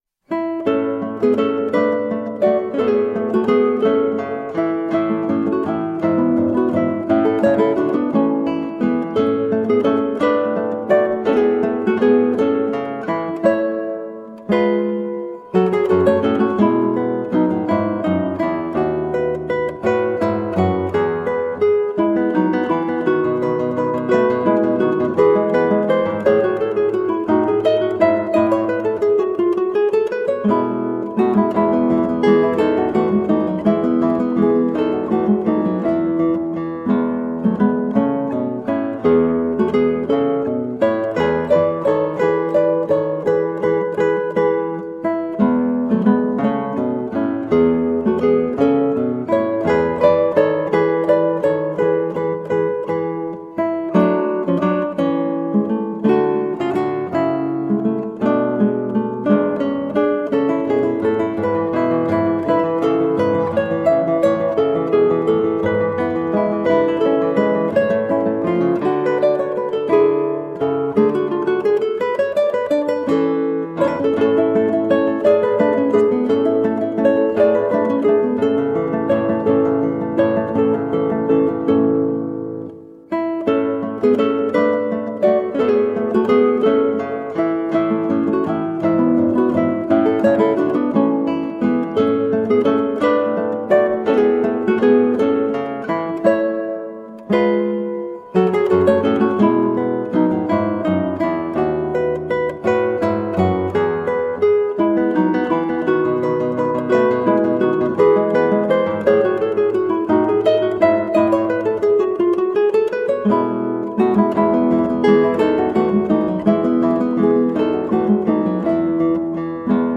Colorful classical guitar.